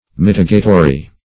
Mitigatory \Mit"i*ga*to*ry\, a.